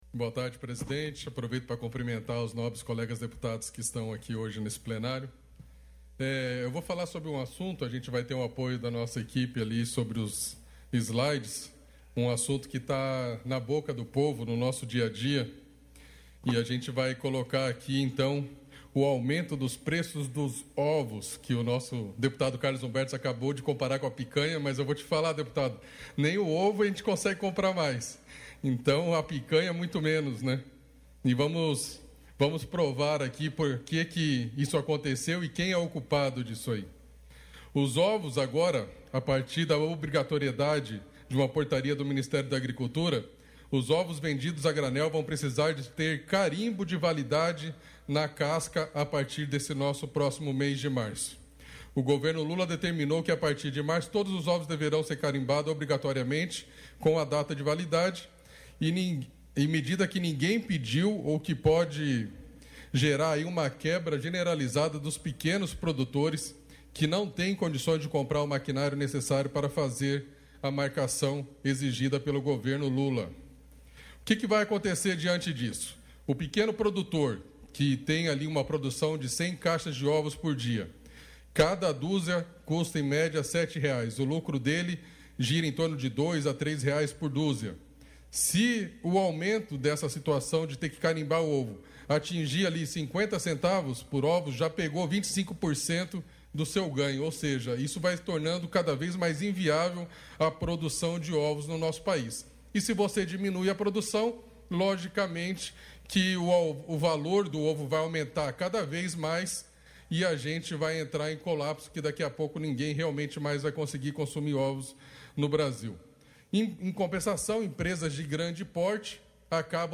Pronunciamentos da sessão ordinária desta quarta-feira (26)
- deputado Alex Brasil (PL);
Dep._Alex_Brasil.mp3